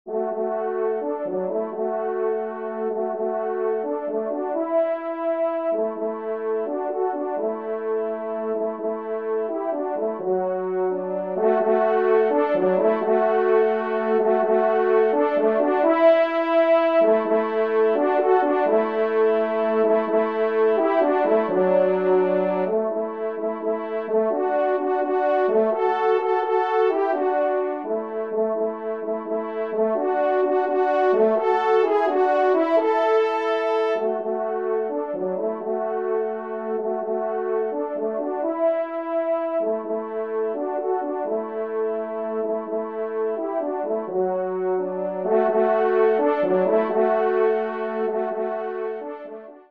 2e Trompe